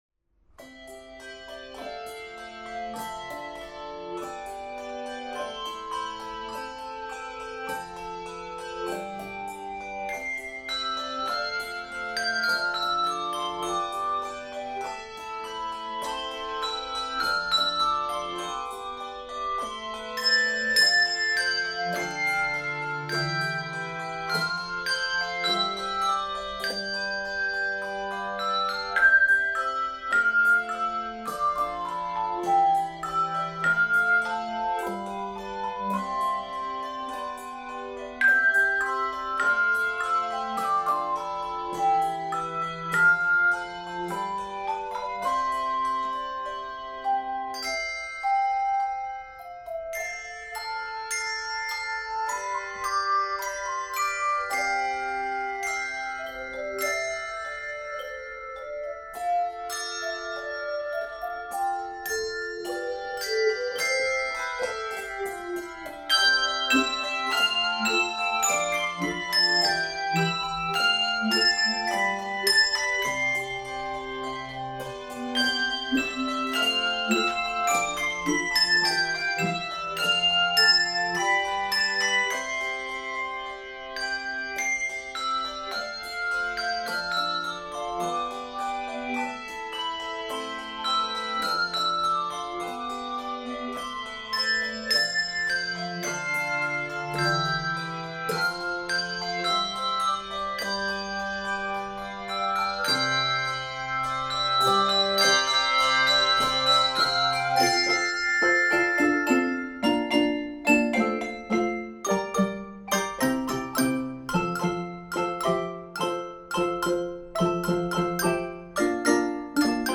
Voicing: 3 Octave Handbells and Handchimes